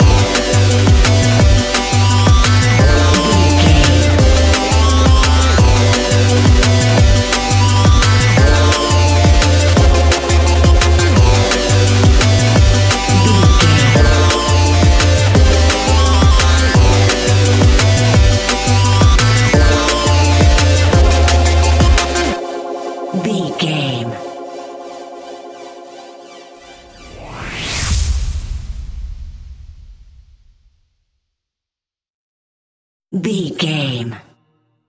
Ionian/Major
F♯
electronic
techno
trance
synths
synthwave